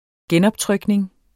Udtale [ ˈgεnʌbˌtʁœgneŋ ]